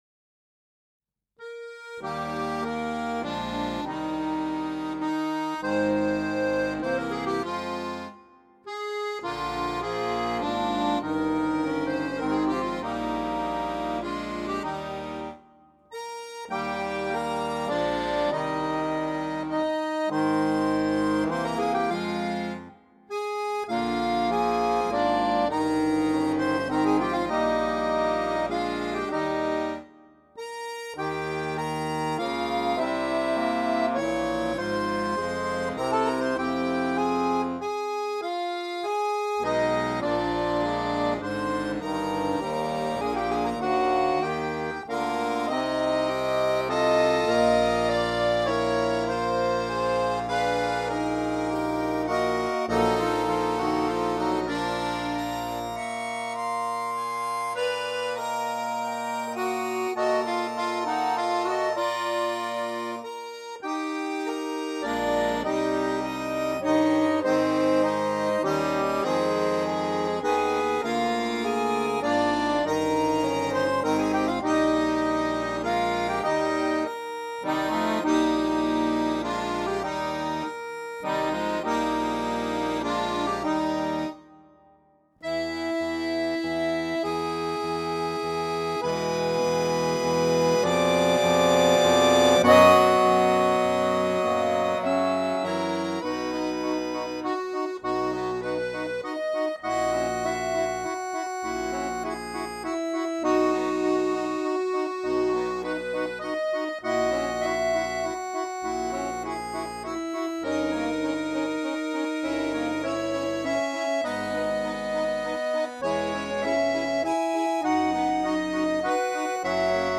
for accordion quintet